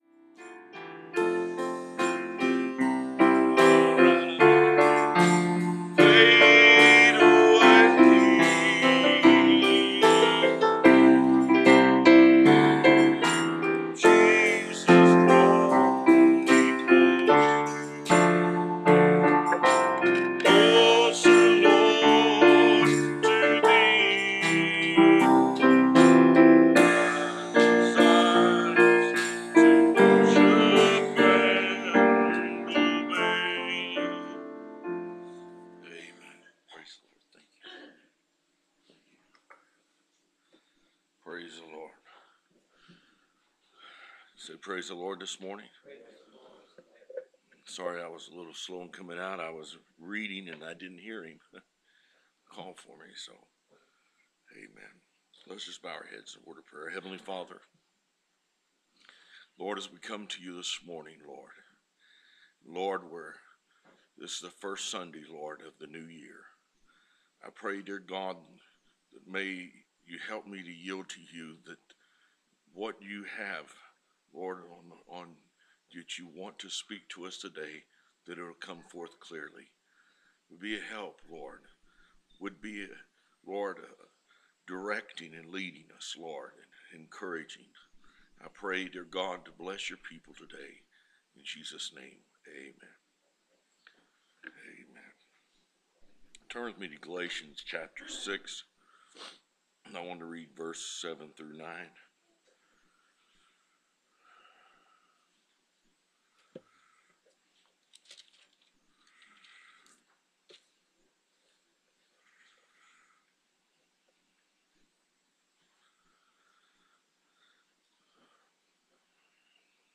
Sermon List: Galatians 6:7 Genesis 1:11 Job 4:8 Proverbs 11:18 Proverbs 6:16